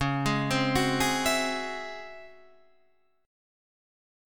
C# Minor Major 11th